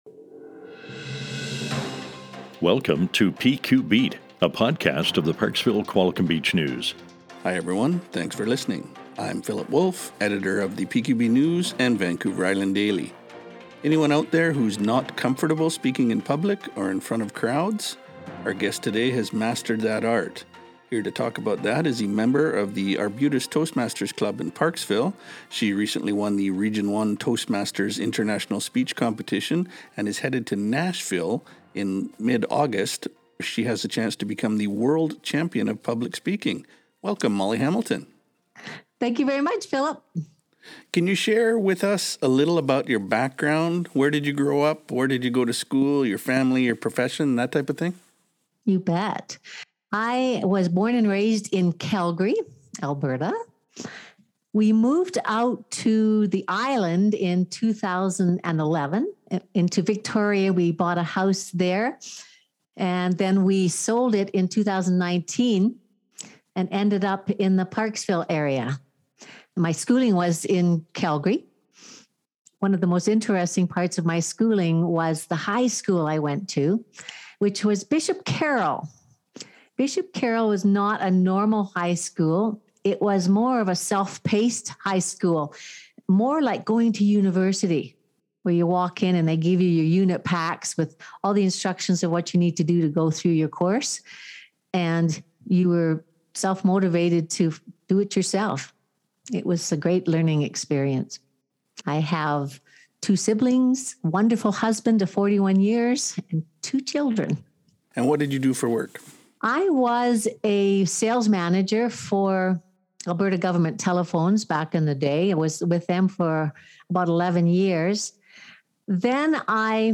Interview with Parksville Qualicum Beech “PQBeat Podcasts” re upcoming Toastmasters Championship of Public Speaking contest – July 8, 2022 TV / Print: CTV News Segment – August 9, 2023
podcast-parksville-qualicum-beach-news2.mp3